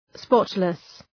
{‘spɒtlıs}